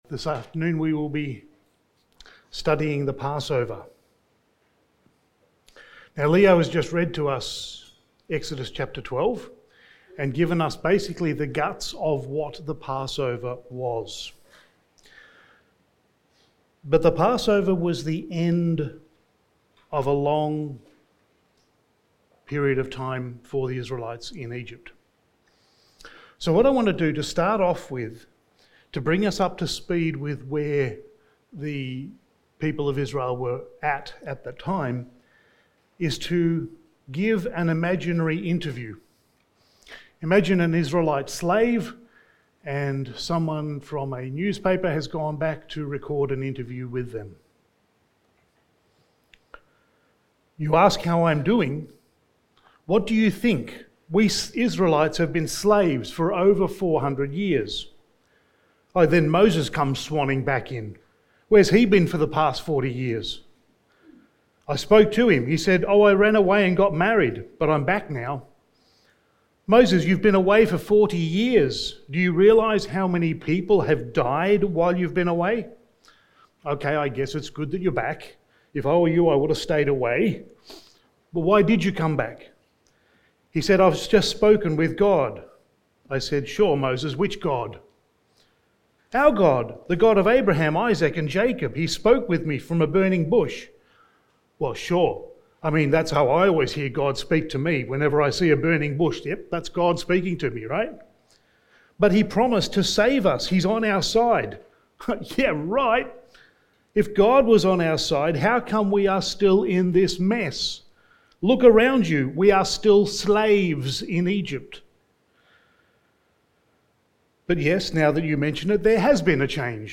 Sermon
Old Testament Pointers to the Cross Series Passage: Exodus 12:1-32 Service Type: Sunday Evening Sermon 4 « Sons and Daughters of God through Faith